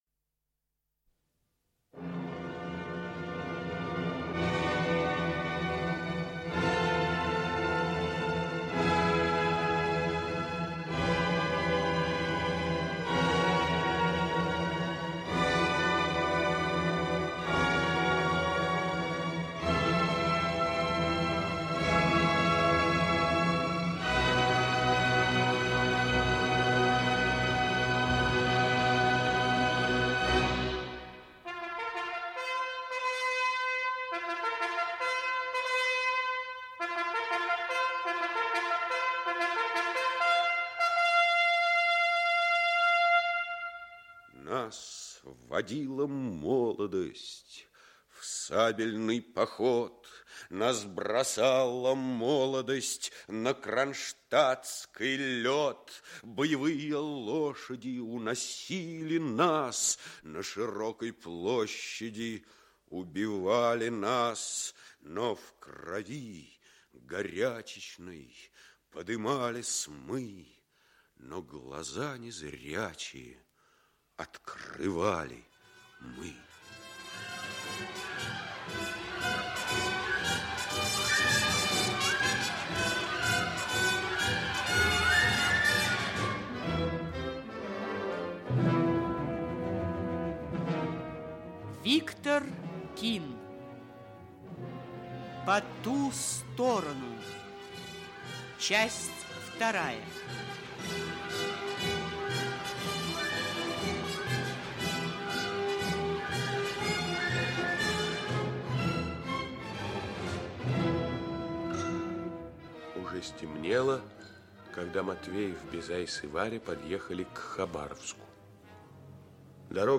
Аудиокнига По ту сторону. Часть 2 | Библиотека аудиокниг
Часть 2 Автор Виктор Кин Читает аудиокнигу Олег Табаков.